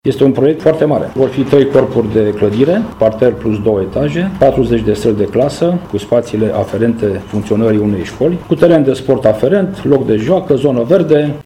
Primarul municipiului Săcele, Virgil Popa: